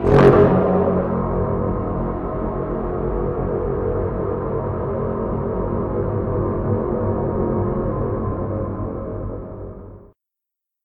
HornLarge.ogg